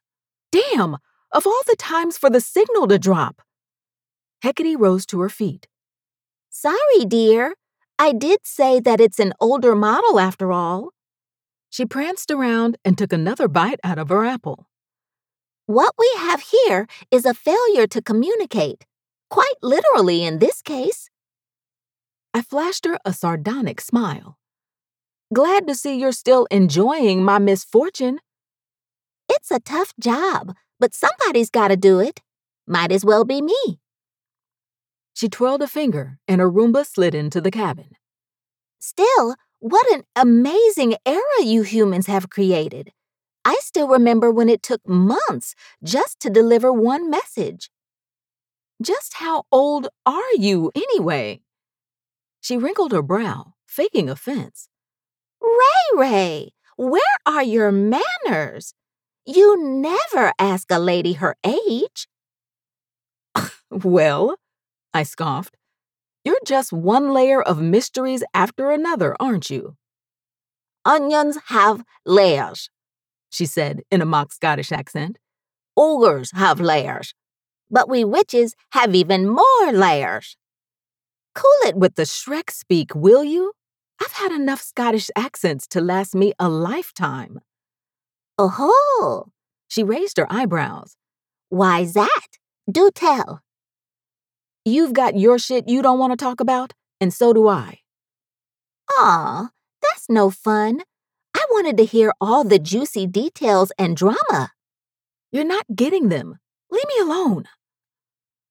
F/F 1st POV Fantasy Fiction
My voice is often described as warm and feminine, with a depth that brings a unique richness to every story I narrate.
I believe in delivering high-quality audio, and to achieve this, I use top-notch home studio equipment, including a Double-Walled VocalBooth, Neumann TLM 102, RØDE NT1 5th Gen, Sennheiser 416, and a Focusrite Scarlett 2i2 interface.